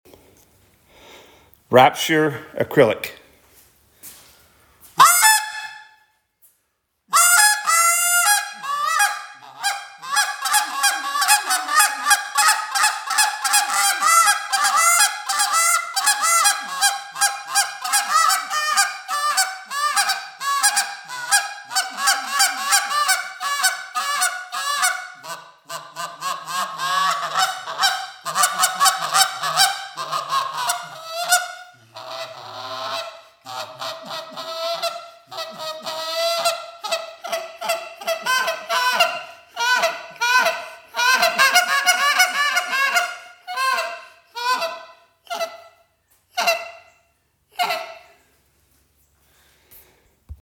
Canada Goose Call
The Rapture is a powerful, short call that delivers aggressive clucks, murmurs, and honks.
• Versatile Sound Range: The more open insert allows for enhanced volume and a broad spectrum of realistic goose vocalizations.